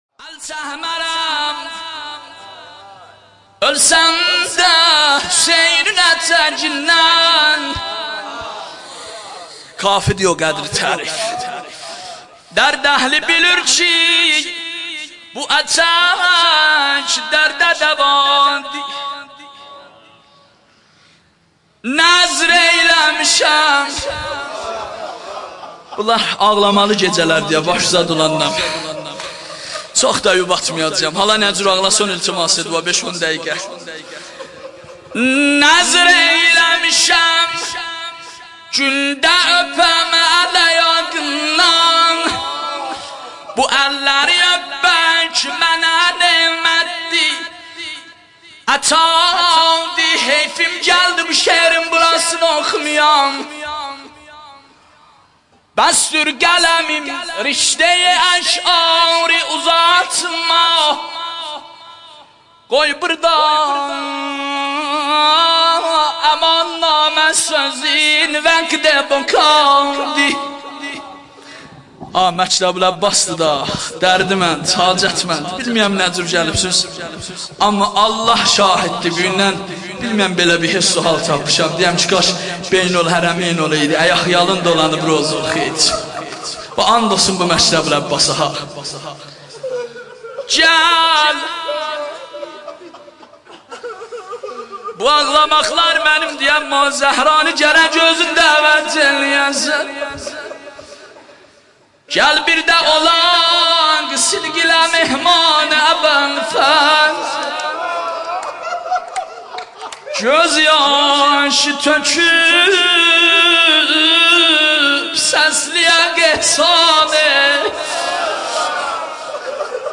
روضه ترکی حضرت ابوالفضل العباس (ع)